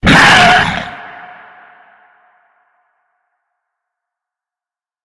Divergent / mods / Soundscape Overhaul / gamedata / sounds / monsters / lurker / ~hit_0.ogg
~hit_0.ogg